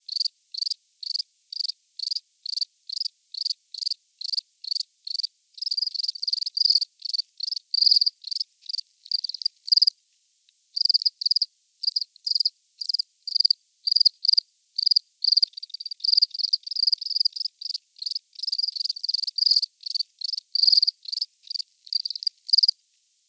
insectnight_7.ogg